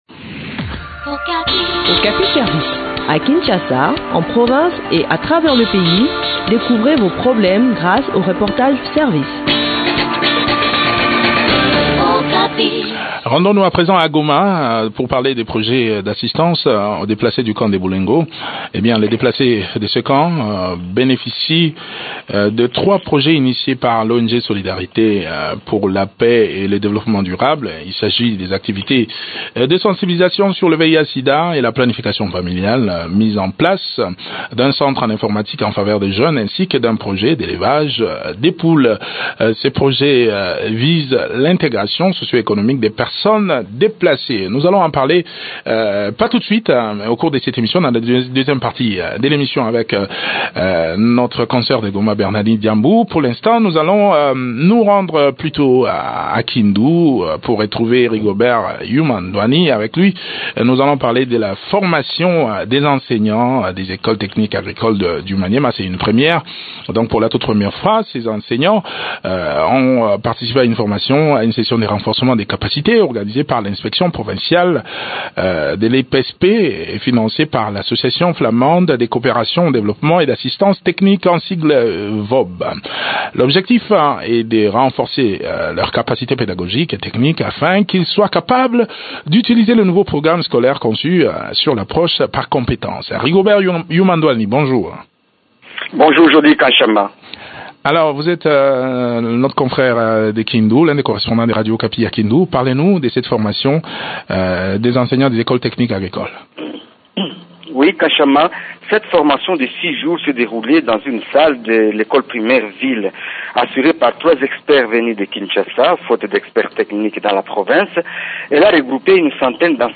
Le point sur l’exécution de ces projets dans cet entretien